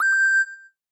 beginningSignal2.ogg